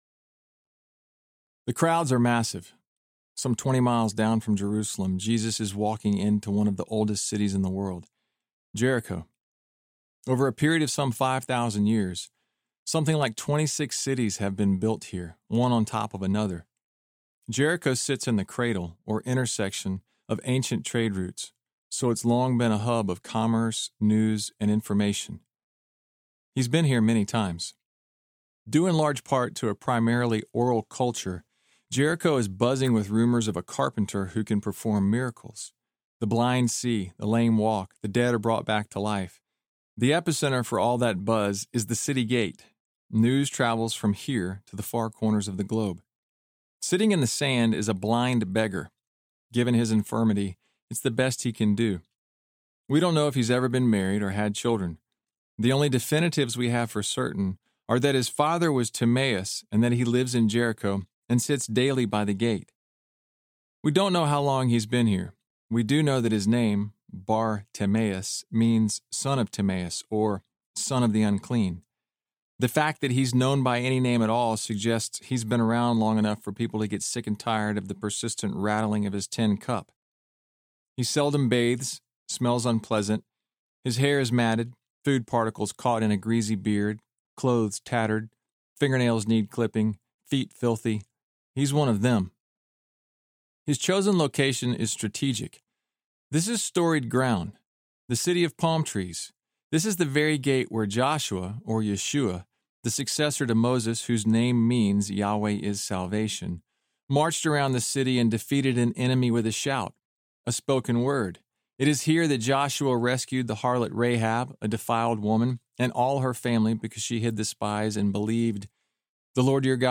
What If It’s True? Audiobook